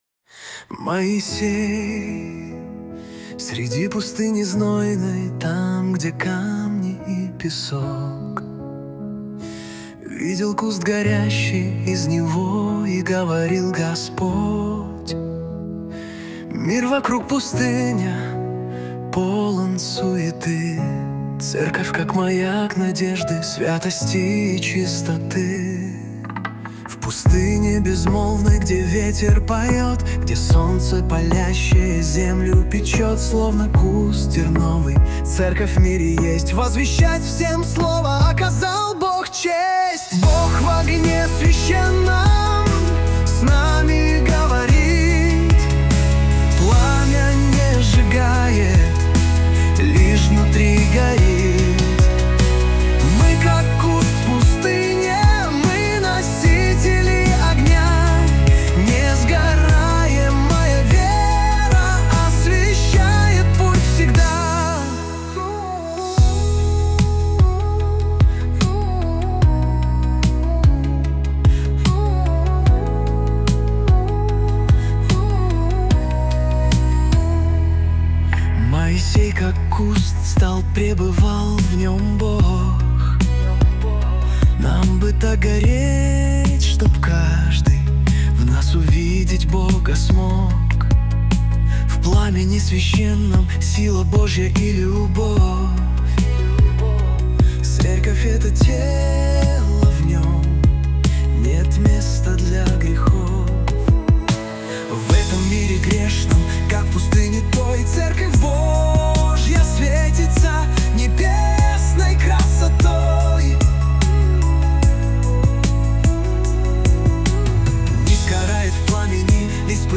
песня ai
168 просмотров 1000 прослушиваний 68 скачиваний BPM: 75